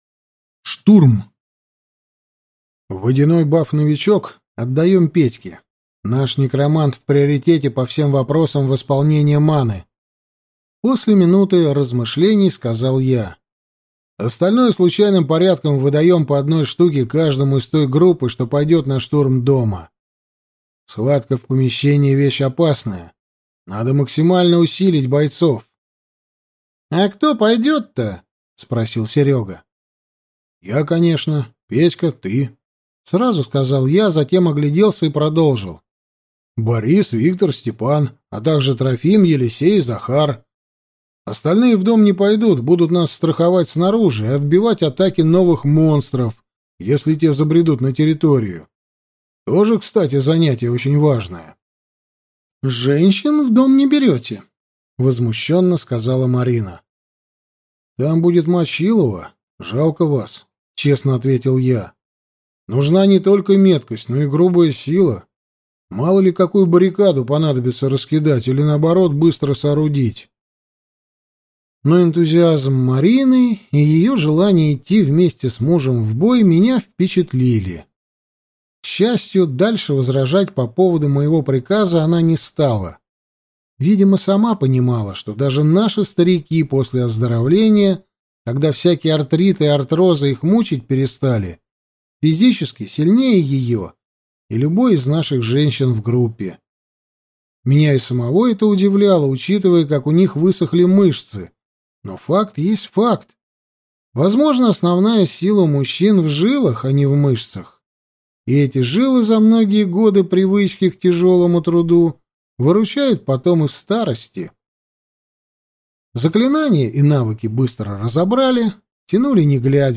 Аудиокнига Рубеж 5: На острие атаки | Библиотека аудиокниг